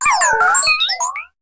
Cri de Spododo dans Pokémon HOME.